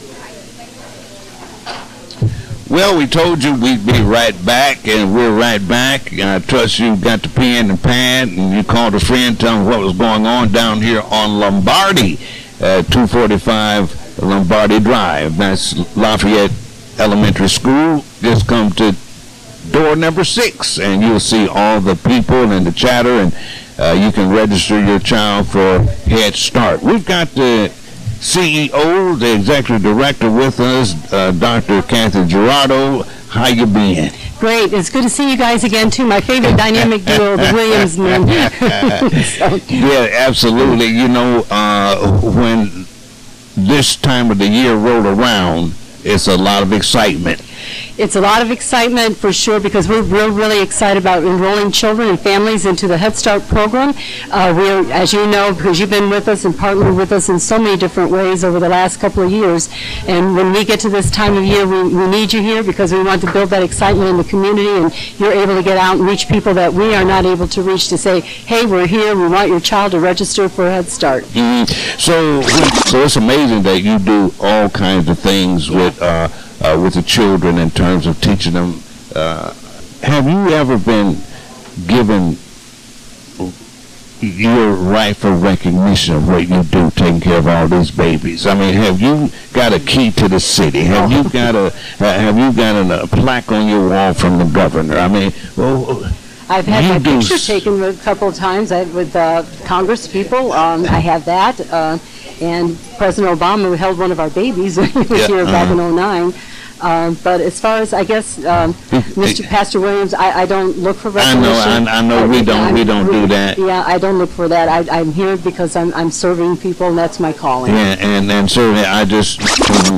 A stand-alone radio program, or a show produced by a non-commercial radio station as part of an ongoing series.